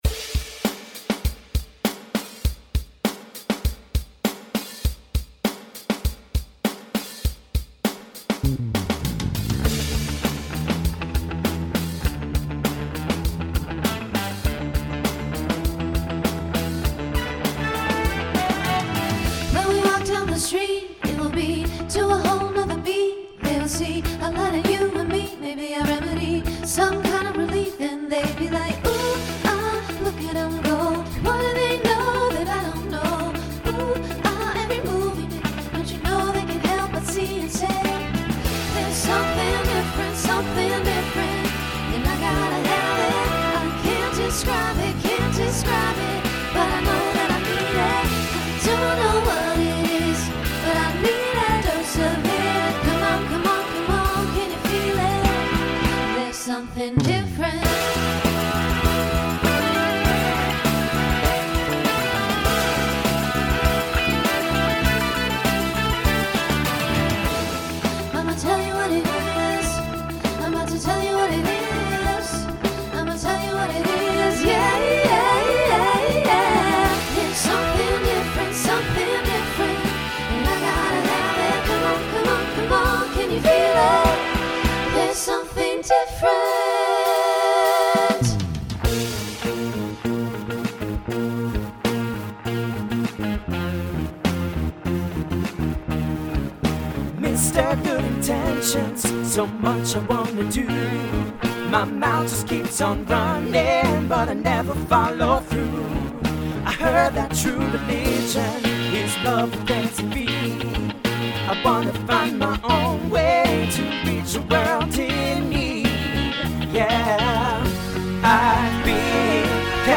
SSA/TTB